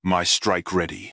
This product is the supporting voice of my self-made unit "Messiah Tank", which is completely self dubbed and not taken from any RTS in the same series.
These voices were completely recorded by me personally, with almost no post-processing, which makes them very versatile.
My spoken English may have a taste of Chinglish.